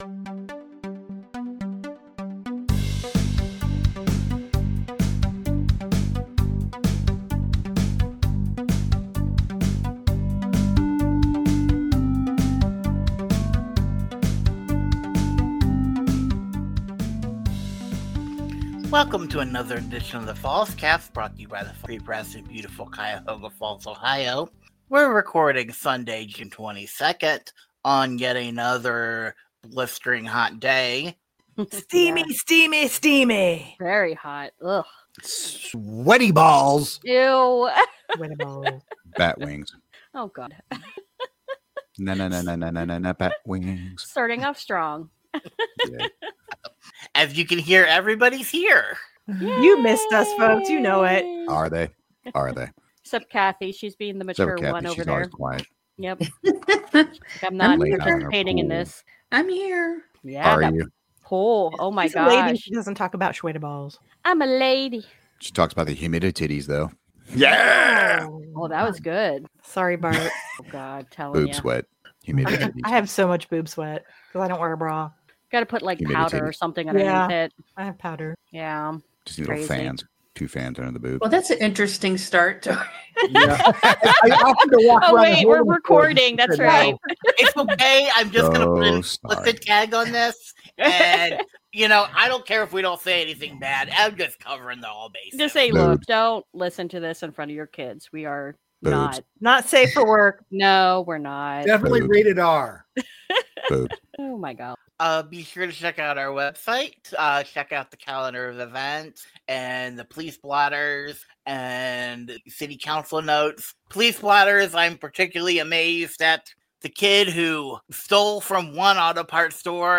Discussion on: Protests Gordon Ramsey at Lyla’s Missing 62-year-old man at Blossom Overturned kayak near Sheraton Summit Co Environmental Services building demolished Summit Arts Collective Summer …